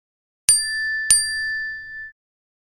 Звук Стекло iPhone